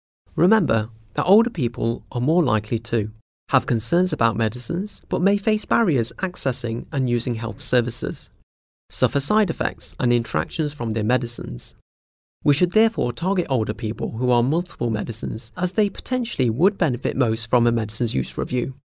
Narration audio (MP3) Narration audio (OGG) Select which of the following statements are true or false?